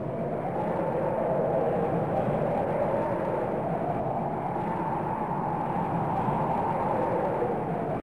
snow.ogg